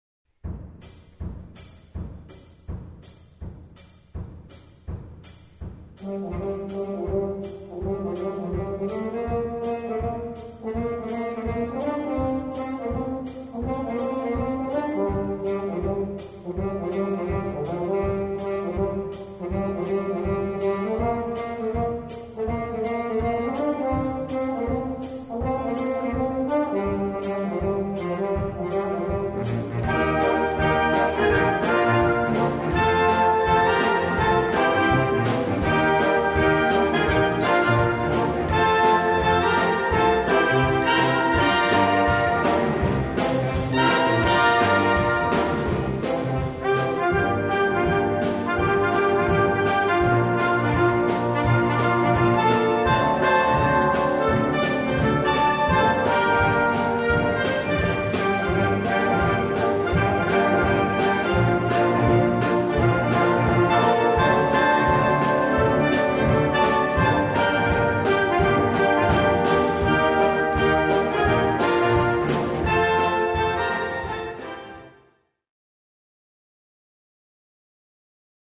Gattung: Chanson francaise
3:15 Minuten Besetzung: Blasorchester Zu hören auf